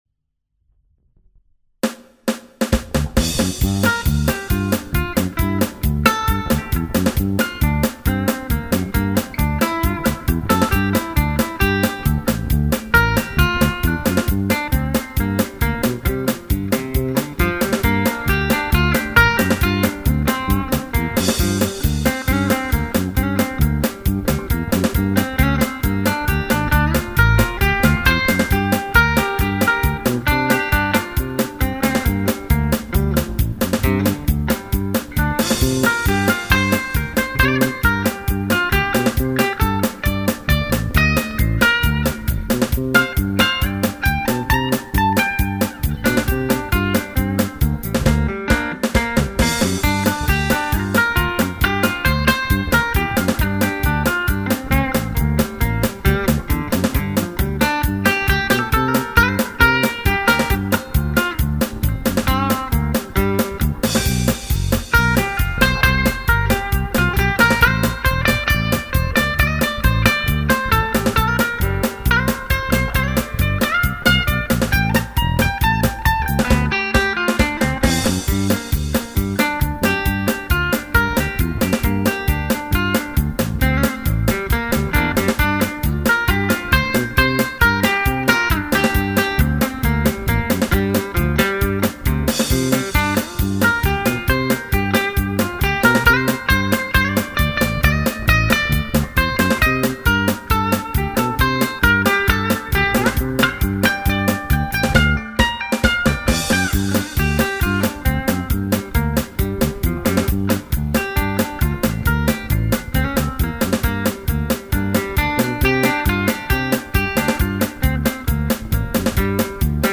Die alte Version habe ich mit der Philgood und ner Bluesscale eingespielt und ich finde, das hört man auch.